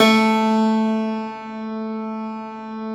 53e-pno09-A1.wav